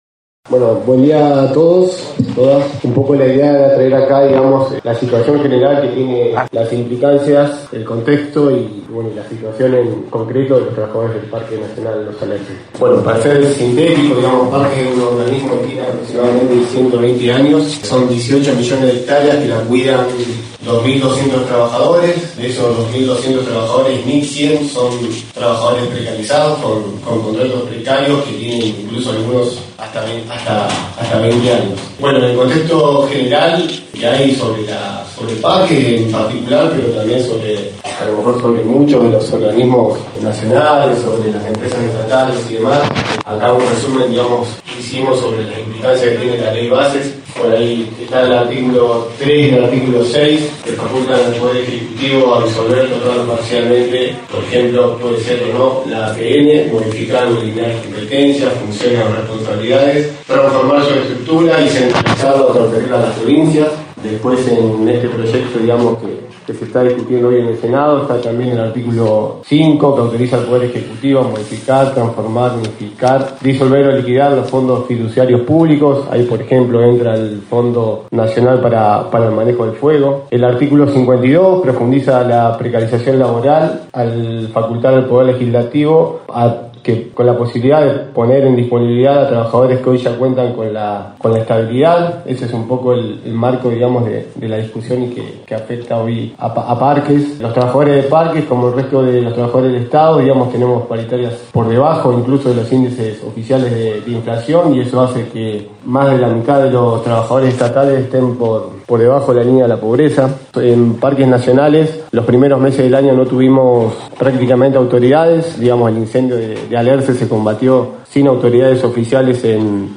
En el marco de la sexta sesión ordinaria del Concejo Deliberante, los trabajadores del Parque Nacional Los Alerces se presentaron a través de la Banca del Vecino, para dar a conocer la situación de incertidumbre que atraviesan ante las políticas implementadas por el Gobierno Nacional.